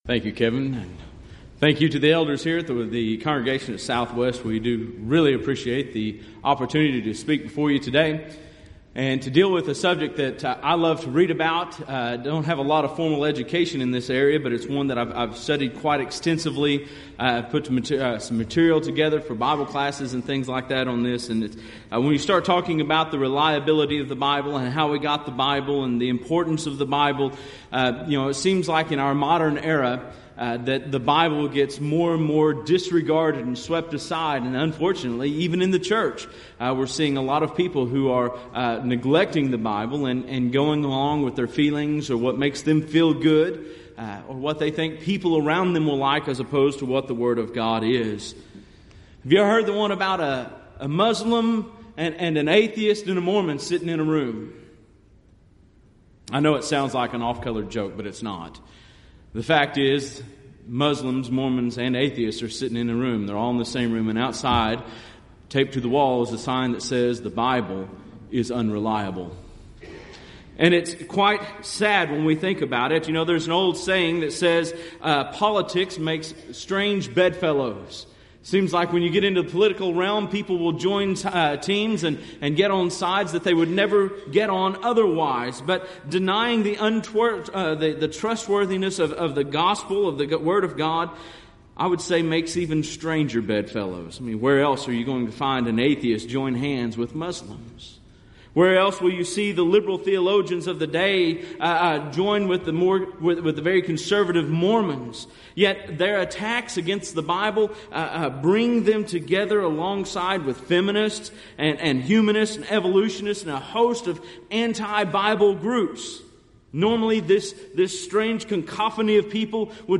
Series: Southwest Lectures Event: 30th Annual Southwest Bible Lectures